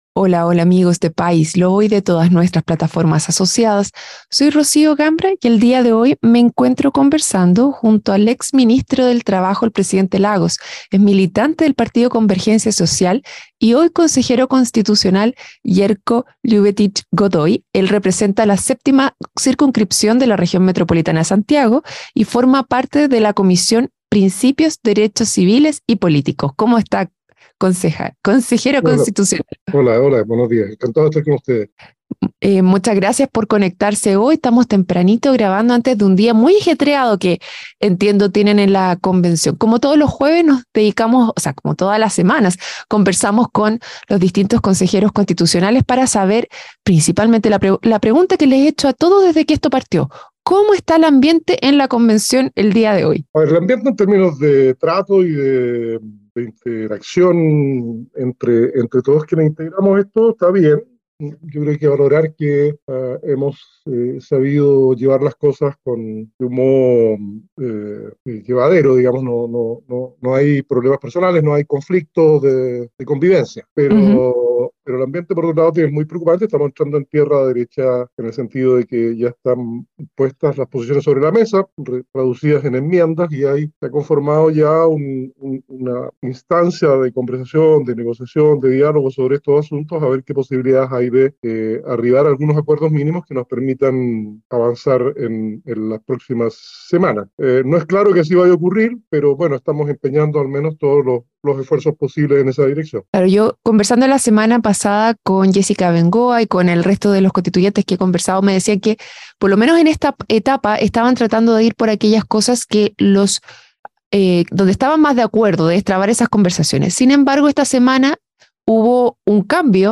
En su emisión más reciente, conversó con el Consejero Constitucional Yerko Ljubetic, perteneciente a la Comisión de Principios, Derechos Civiles y Políticos.